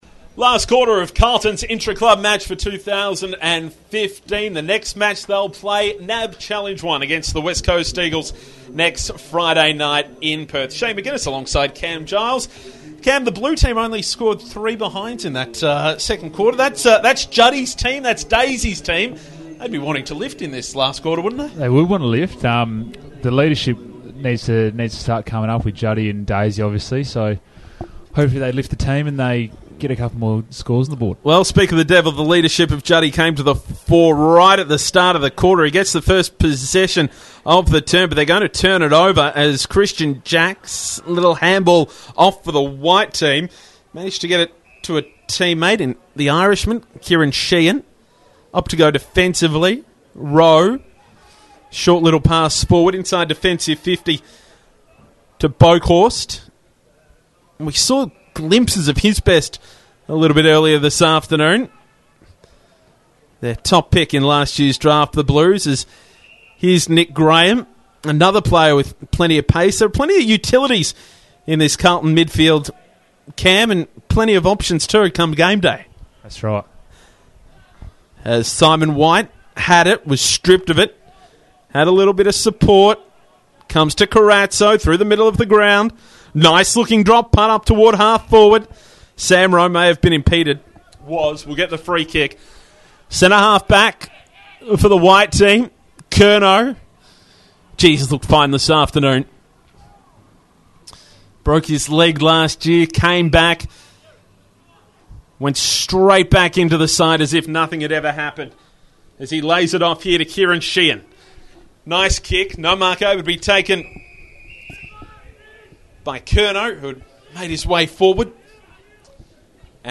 calls all the action of the final quarter of Carlton's intra-club match.